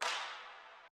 Claps
Metro Claps [Tape].wav